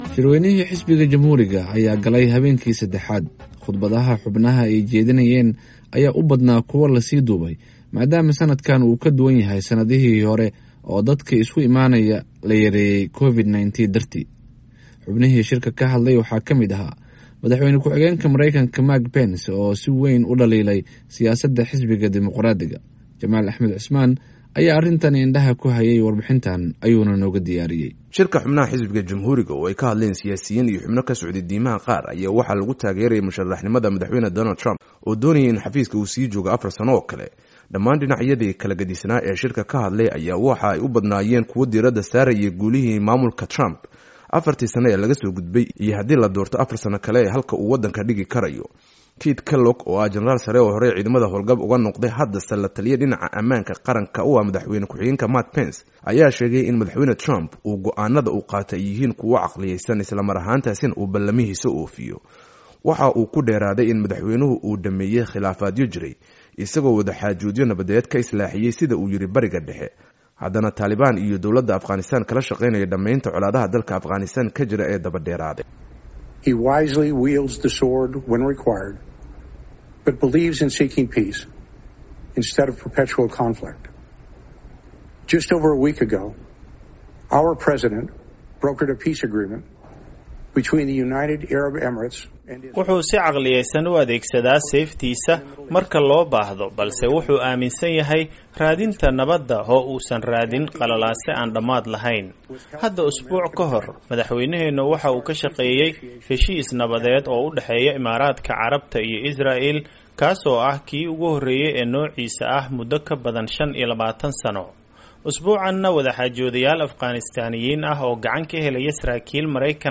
Warbixin: Habeenkii 3-aad ee Shirweynaha Jamhuuriga